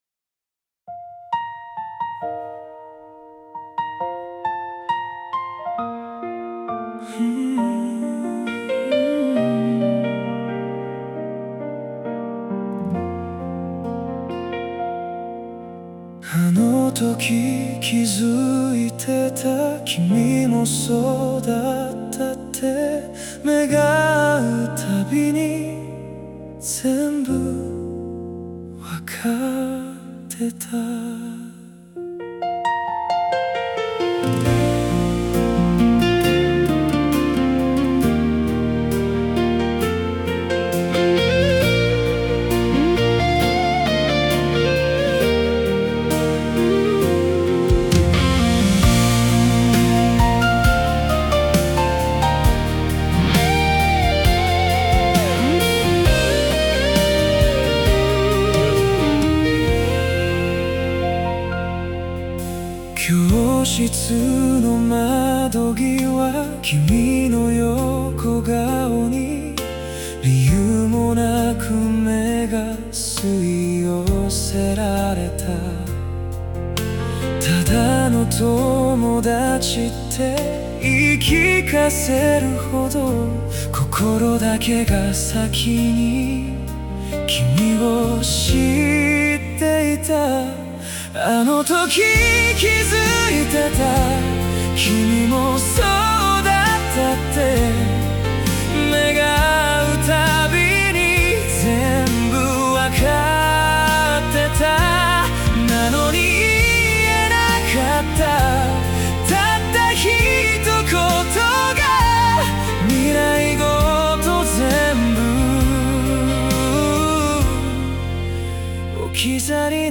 男性ボーカル
イメージ：エモーショナル・バラード,男性ボーカル,切ない,好きの形,本当の初恋,失恋